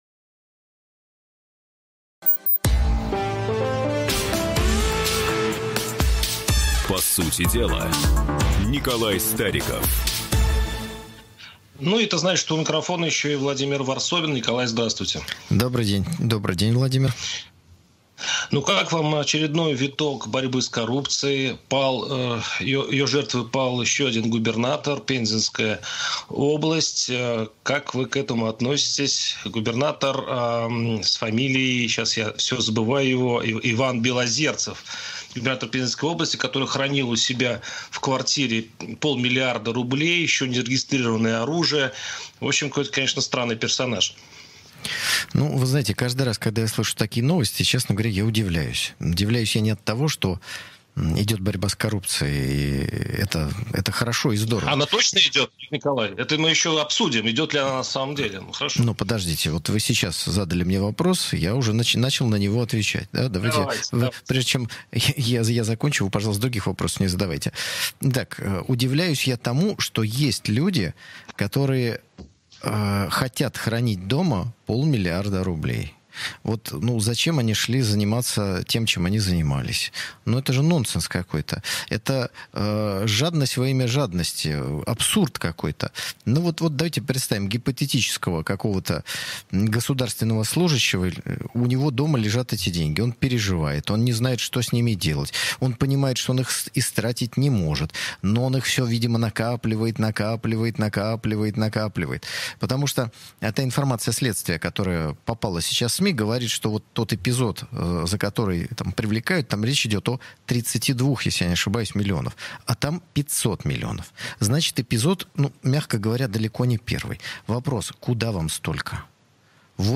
В очередном выпуске программы «По сути дела» на радио «Комсомольская правда» речь зашла об аресте пензенского губернатора Белозерцева и о борьбе с коррупцией в целом, а также поговорили про слова министра иностранных дел Сергея Викторовича Лаврова по отк…